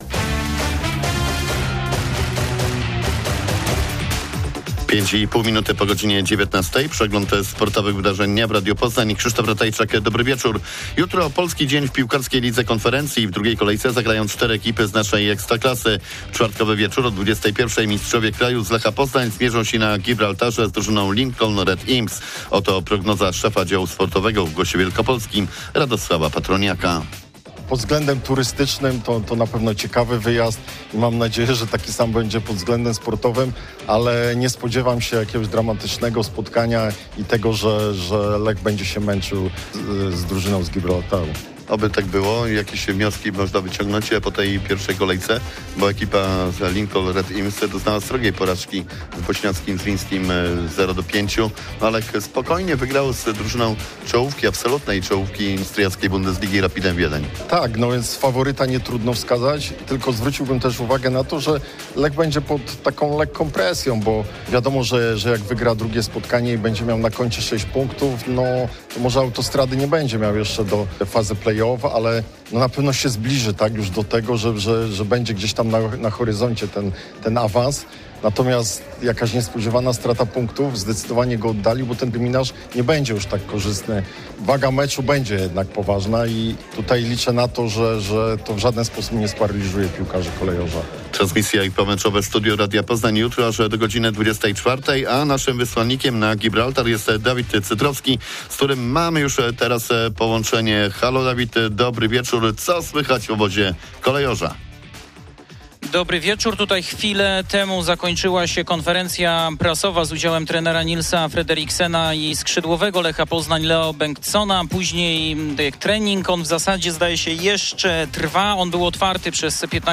22.10.2025 SERWIS SPORTOWY GODZ. 19:05